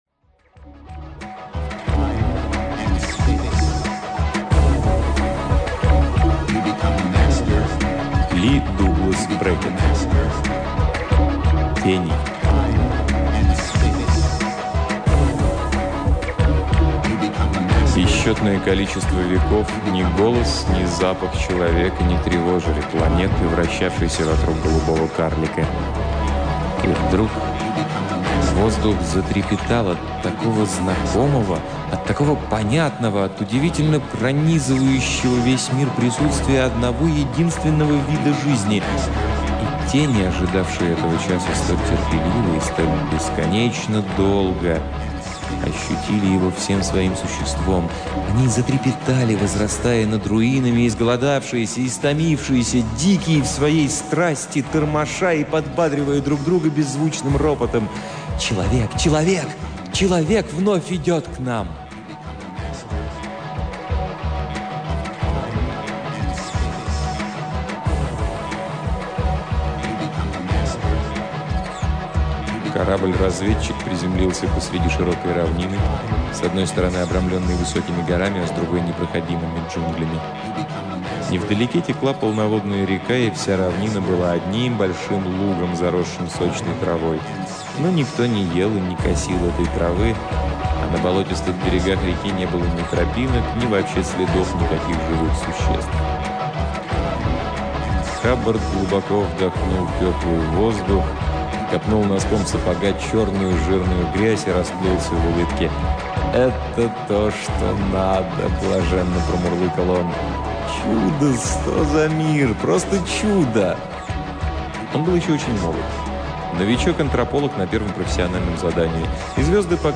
Аудиокнига Ли Дуглас Бреккет — Тени